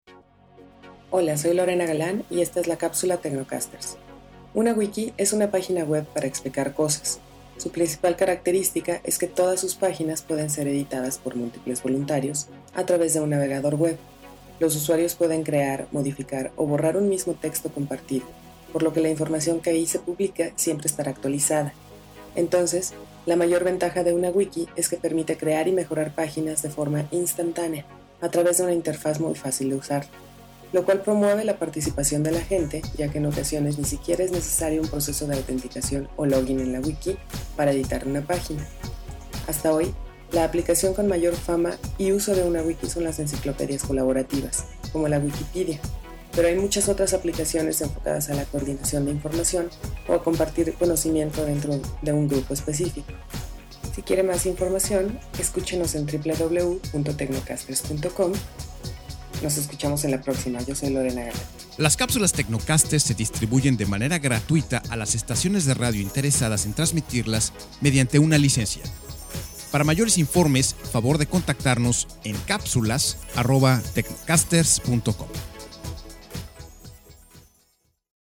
Capsulas para transmision en Radio